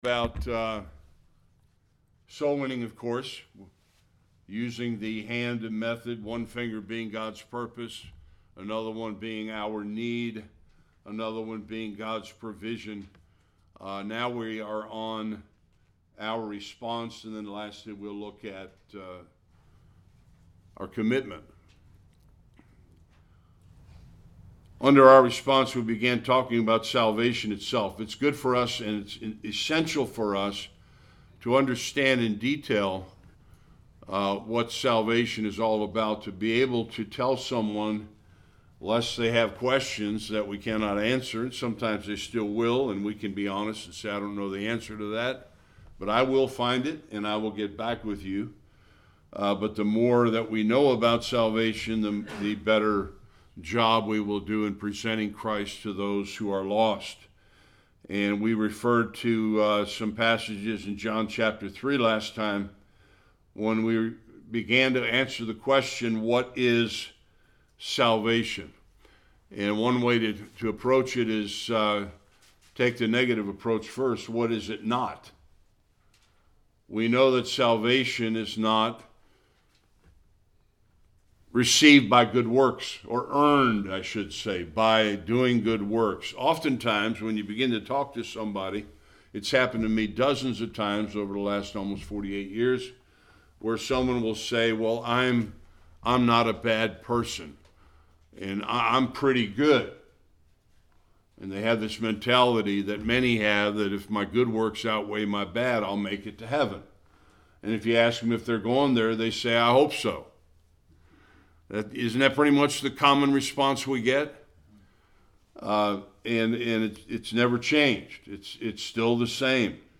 Various Passages Service Type: Sunday School One’s response to the Gospel explained.